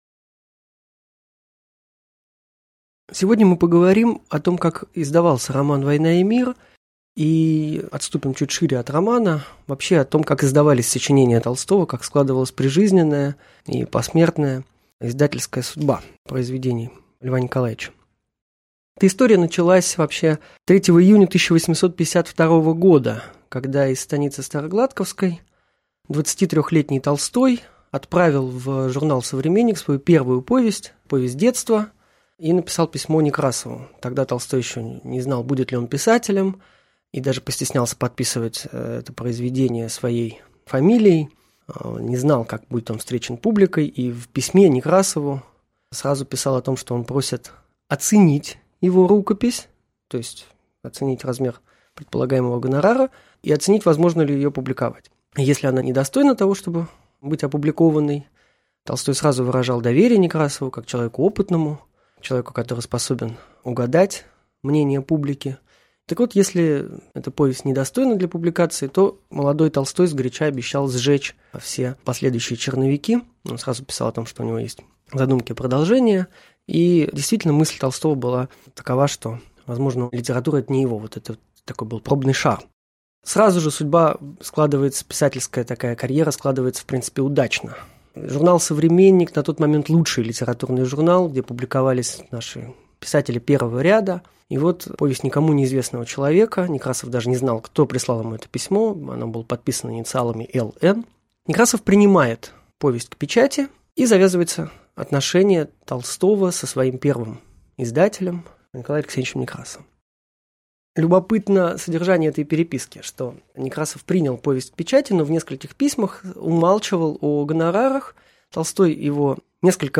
Аудиокнига Лекция «История изданий „Войны и мира“ | Библиотека аудиокниг